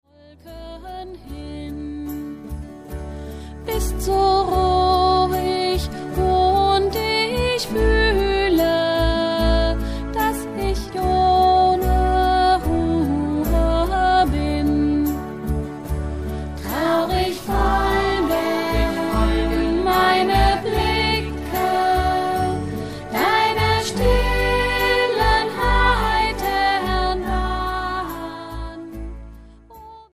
... eine Sammlung bekannter und beliebter Wiegenlieder
eingerichtet für drei Chorstimmen und Begleitung